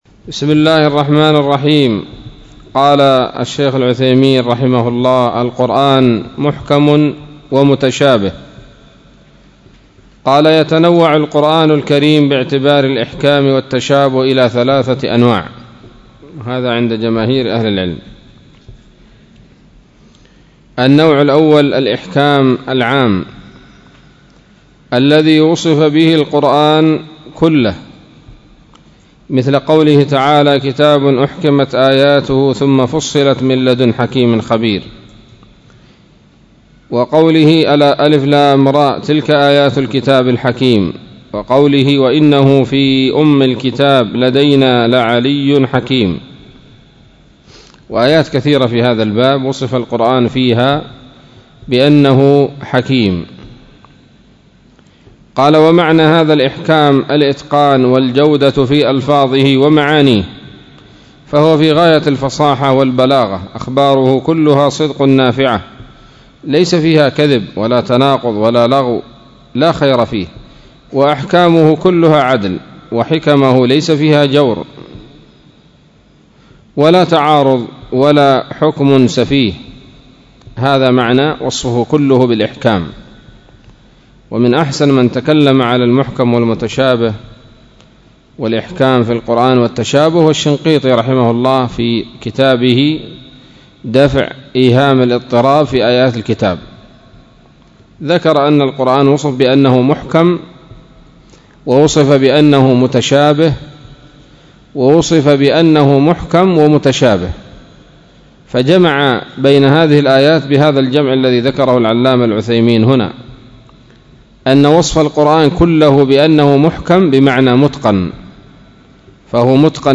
الدرس الثاني والثلاثون من أصول في التفسير للعلامة العثيمين رحمه الله تعالى